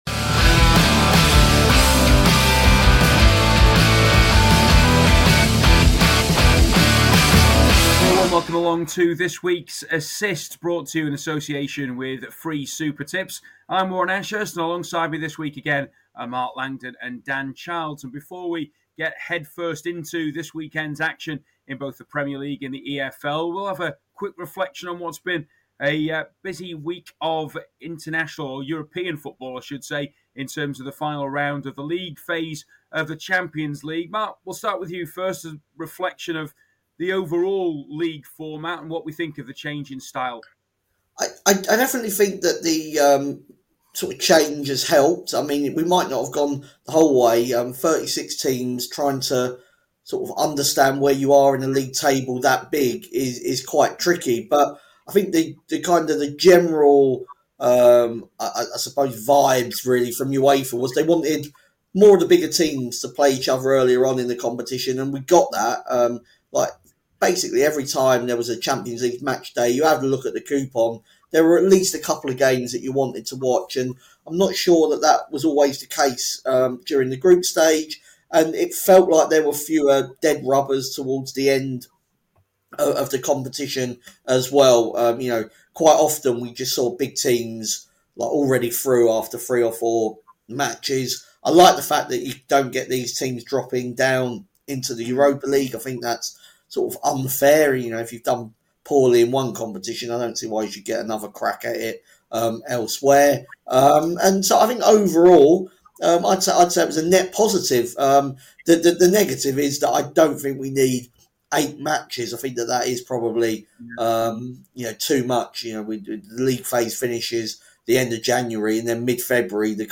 The panel start the show by focusing on the big match this weekend as Arsenal welcome Manchester City to the Emirates.